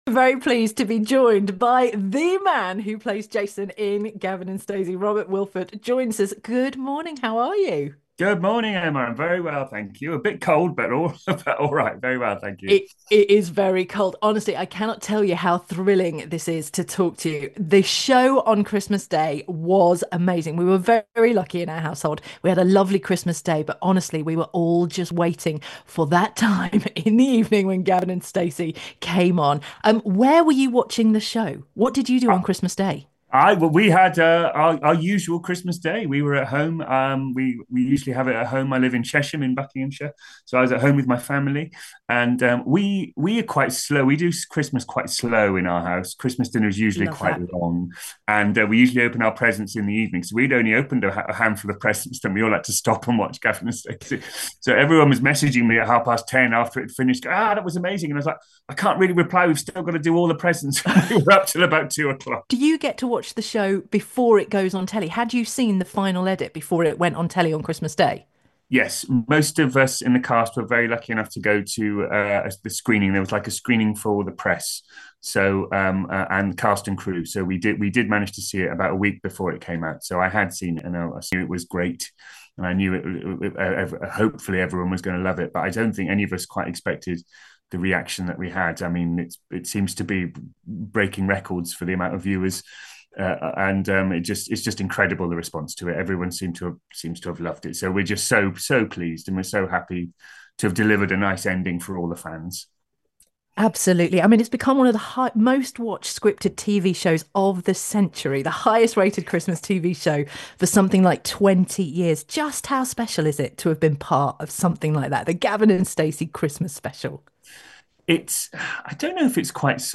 Gavin & Stacey star talks to Bucks Radio about raffling signed script for charity